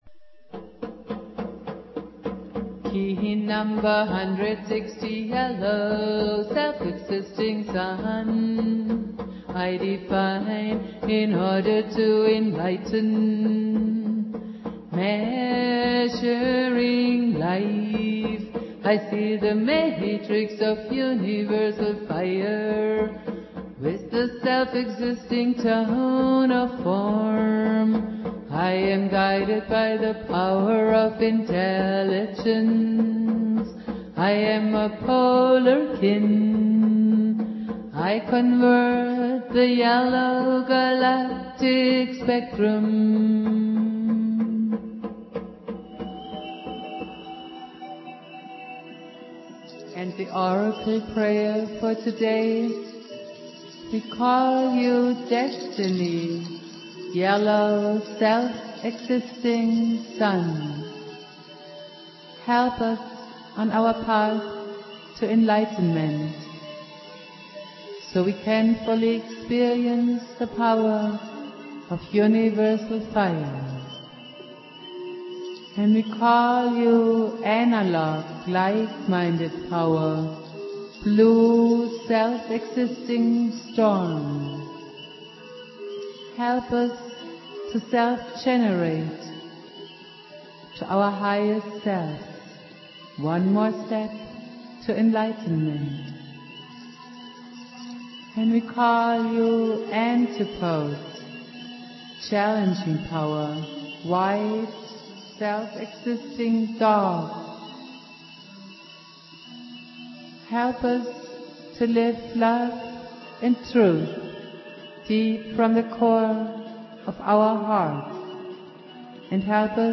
flute
produced at High Flowing Recording Studio